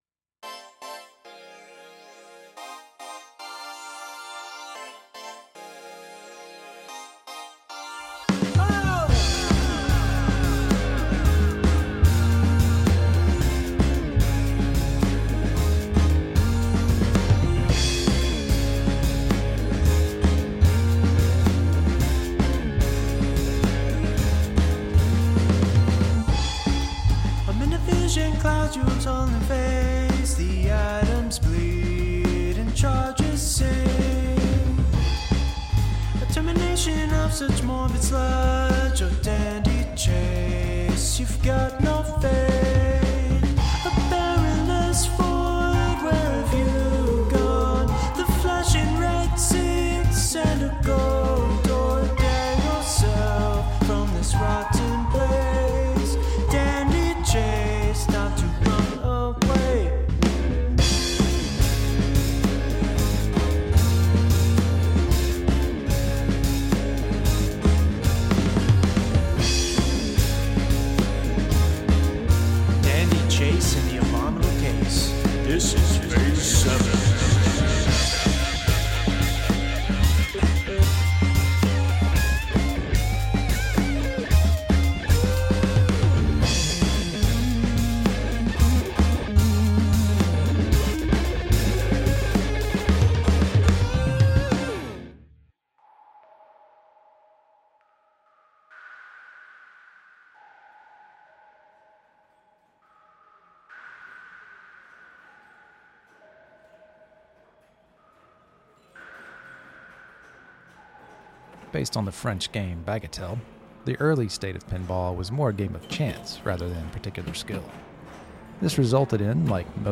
Be a guest on this podcast Language: en Genres: Drama , Fiction , Science Fiction Contact email: Get it Feed URL: Get it iTunes ID: Get it Get all podcast data Listen Now...